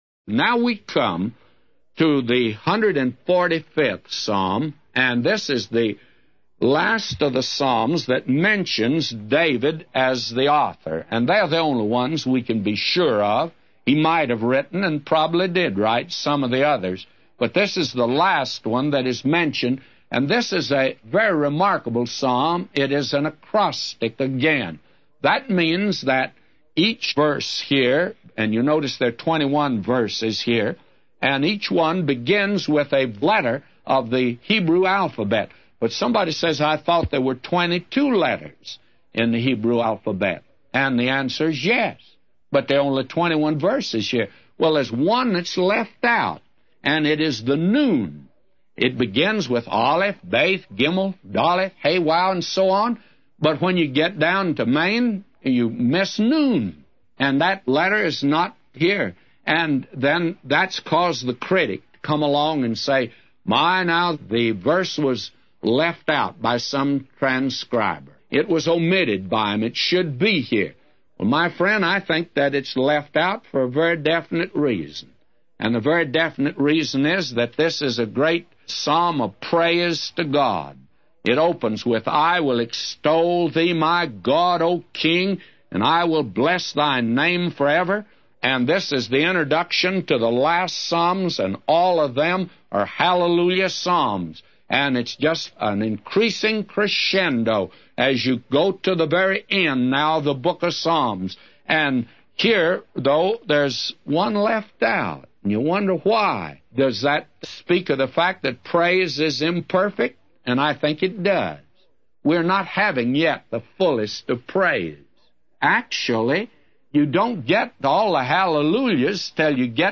A Commentary By J Vernon MCgee For Psalms 145:1-999